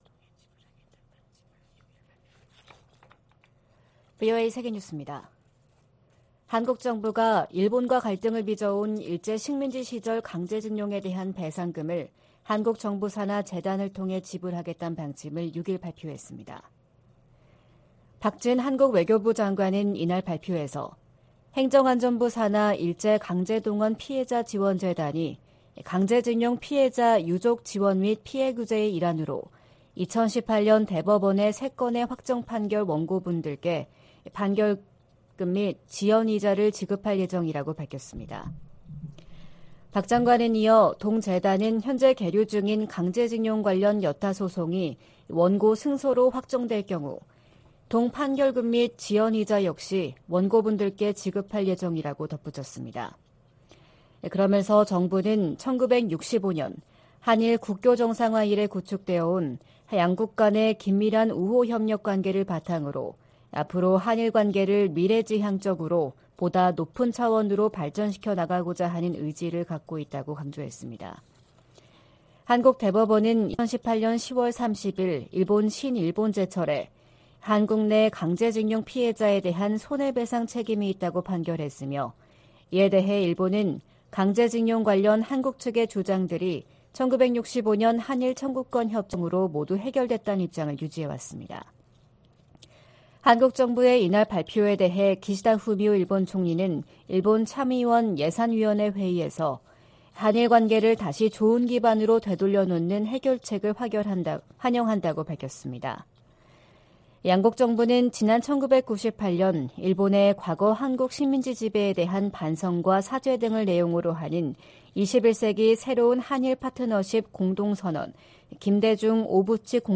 VOA 한국어 '출발 뉴스 쇼', 2023년 3월 7일 방송입니다. 한국 정부가 일제 강제징용 해법으로 피해자들에게 국내 재단이 대신 배상금을 지급한다는 결정을 내렸습니다.